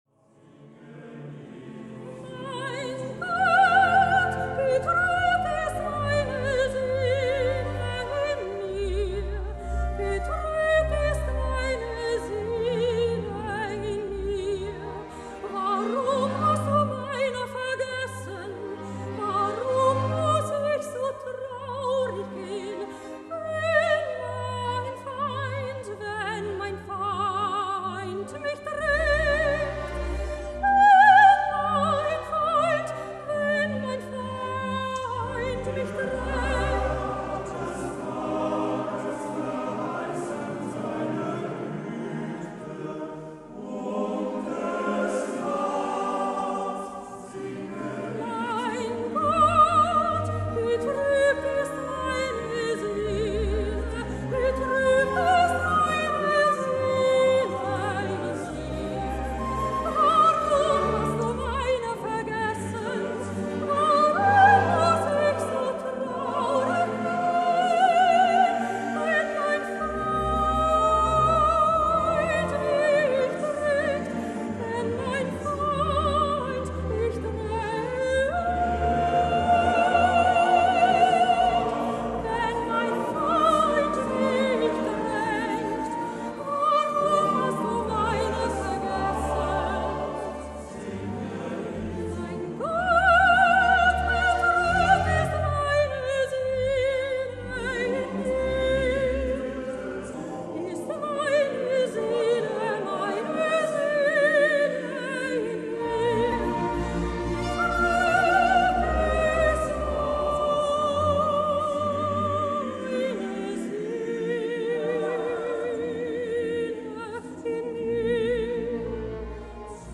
Sopran
Mein Gott! Betrübt ist meine Seele in mir (Mendelssohn – Wie der Hirsch schreit) Sopran mit Männerchor